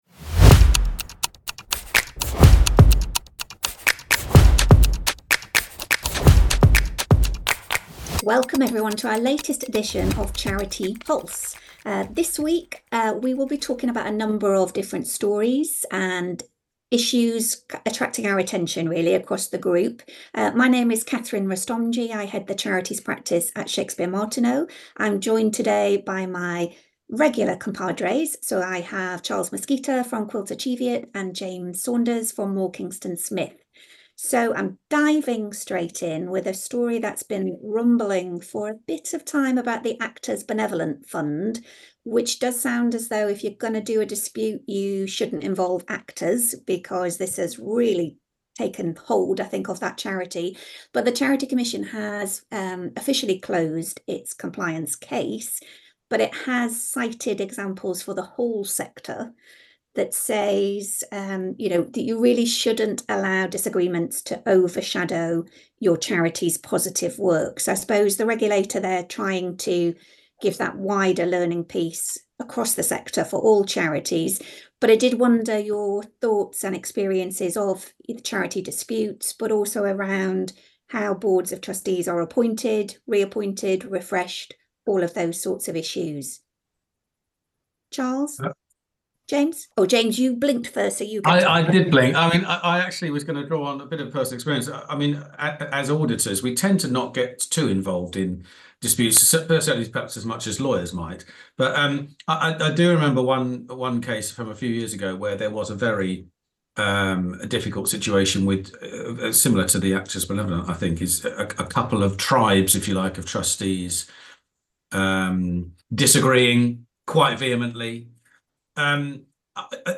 CharitEpulse: Agree to disagree? from CharitEpulse on Podchaser, aired Monday, 1st July 2024.Agree to disagree?How should charities manage internal disputes and ensure that their focus continues to be on delivering their charitable purposes? In this edition, the trio discuss:Charity Governance Cod…